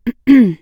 Throat2.wav